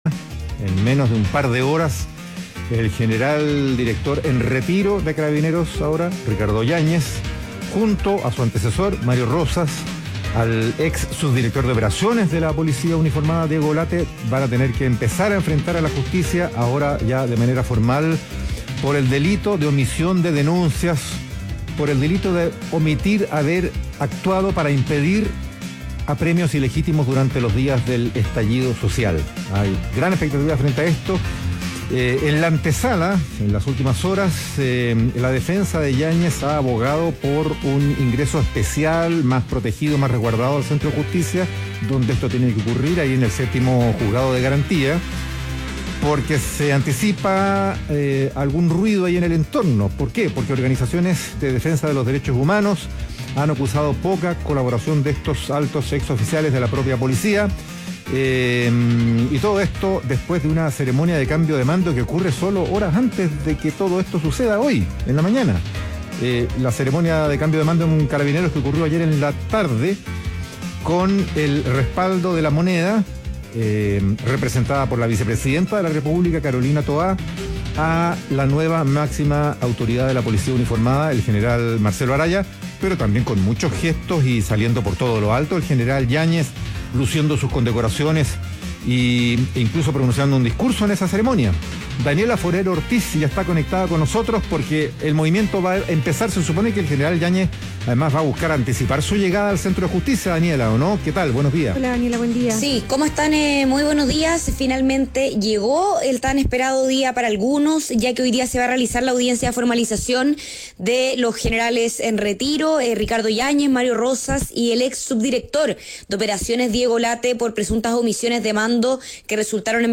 Sigue en vivo la audiencia junto a la transmisión especial de Radio ADN.